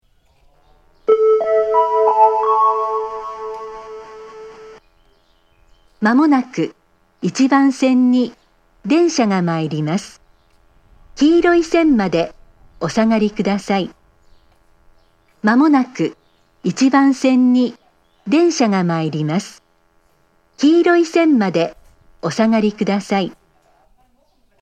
メロディー・自動放送の音が小さい上に、夜間音量が設定されています。
（女性）
接近放送 鳴動開始は到着約2分前です。